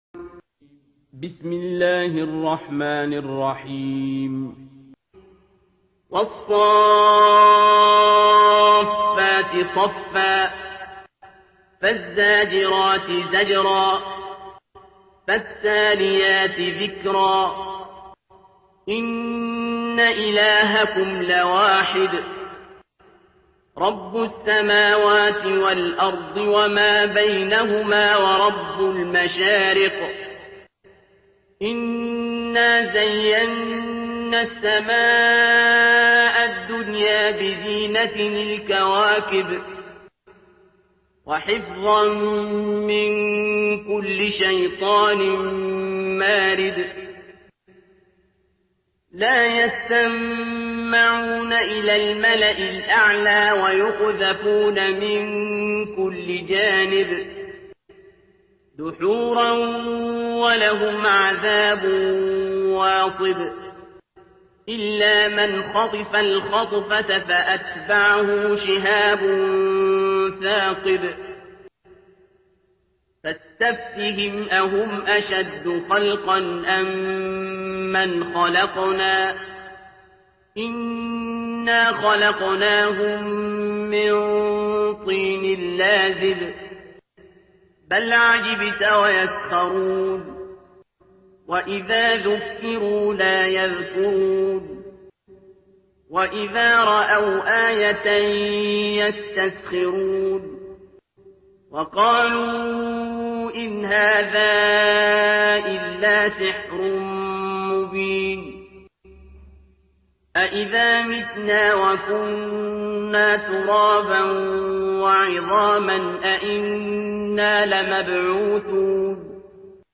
ترتیل سوره صافات با صدای عبدالباسط عبدالصمد